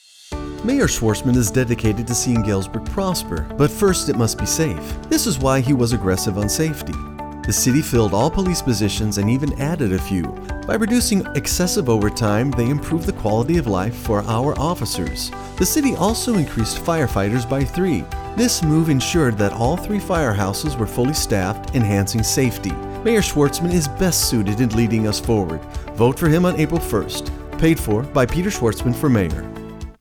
RADIO ADS: